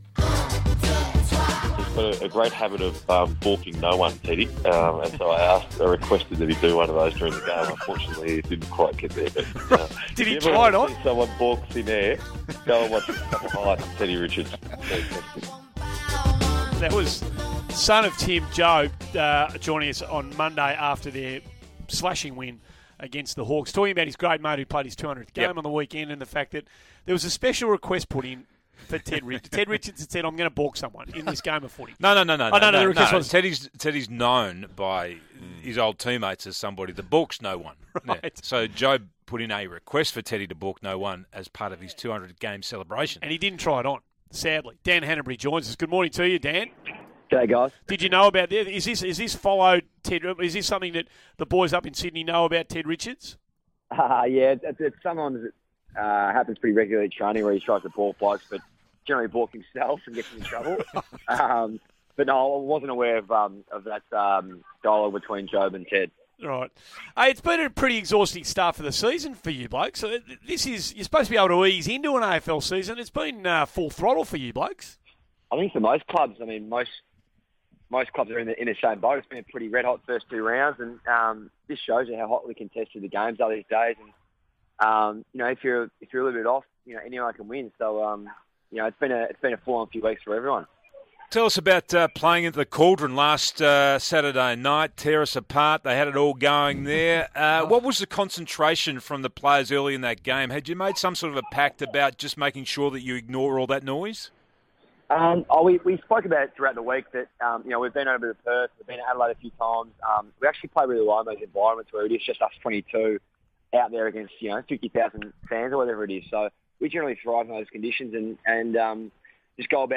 Dan Hannebery - SEN Radio interview
Dan Hannebery speaks to Andy Maher, Tim Watson and Andrew Gaze on SEN Radio.